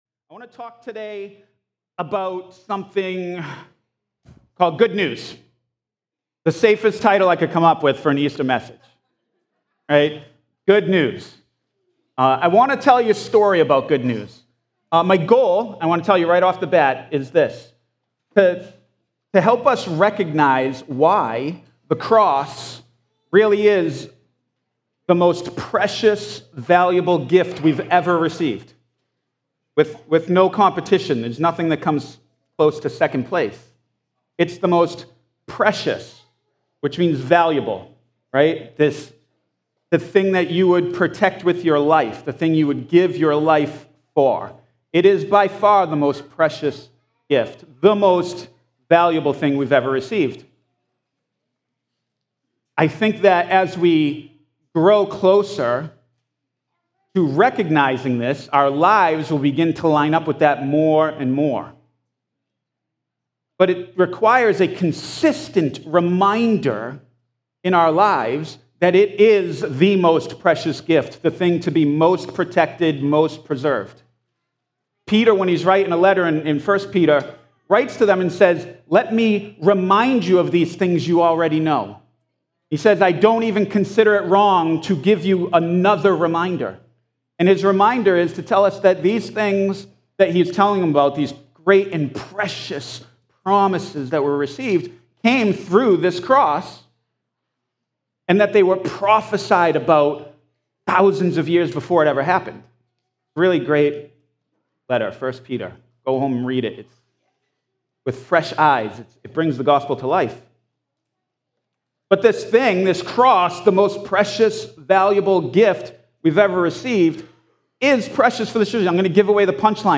4.20.25-Easter-Service.mp3